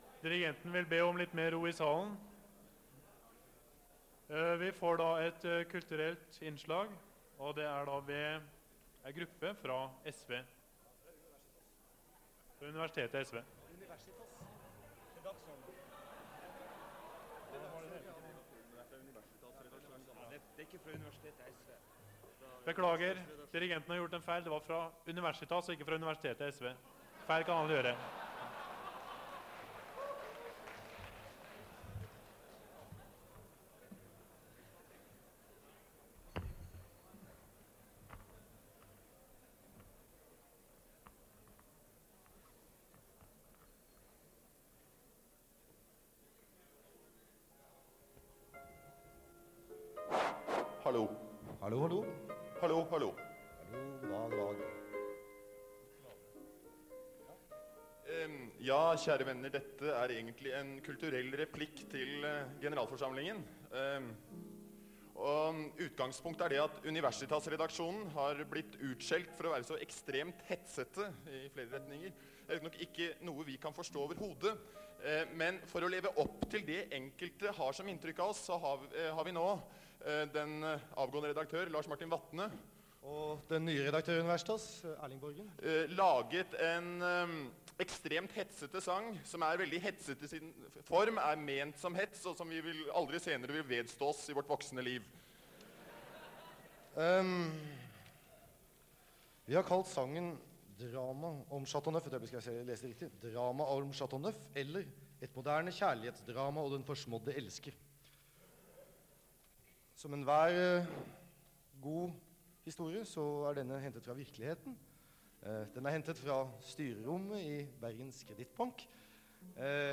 Det Norske Studentersamfund, Generalforsamlinger, Ekstraordinær generalforsamling, 16.11.1974